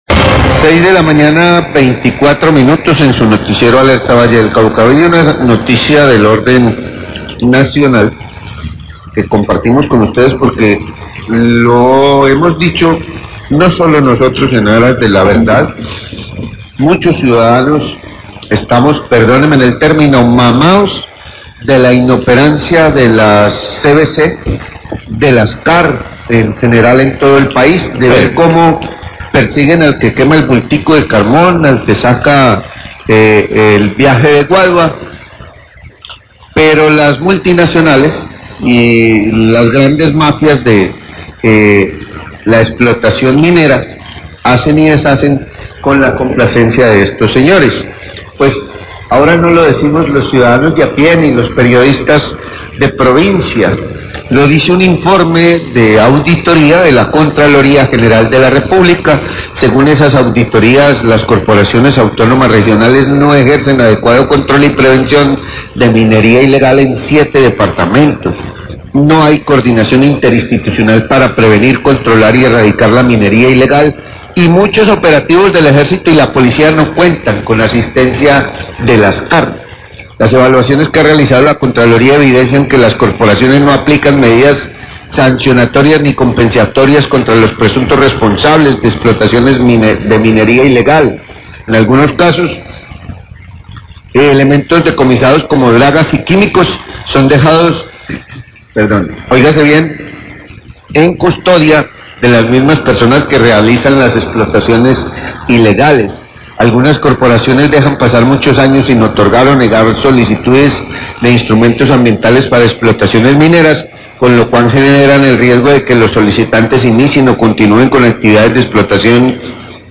Radio
Periodistas señalan que están cansados de la inoperancia de la CVC, dicen que persiguen a los que queman un bulto de carbón y no a las multinacionales y las grandes mafias de la explotación minera son pasadas por alto.